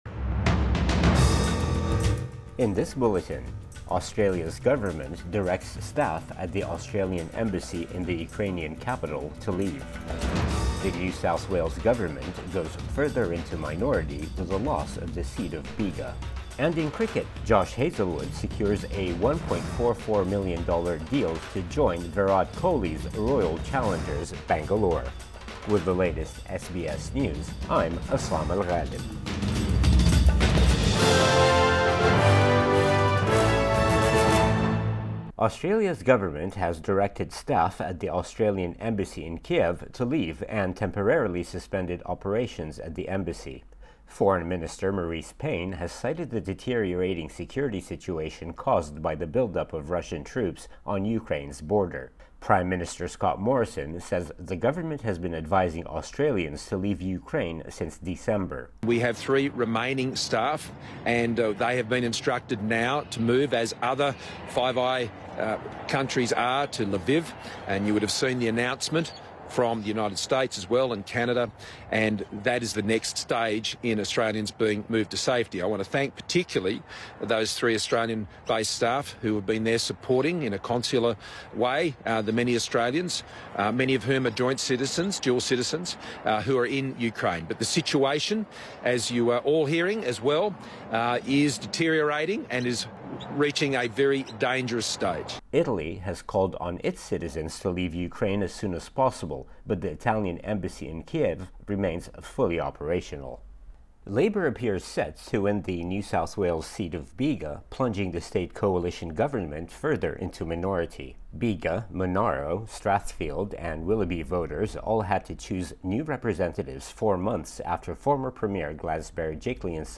Midday bulletin 13 February 2022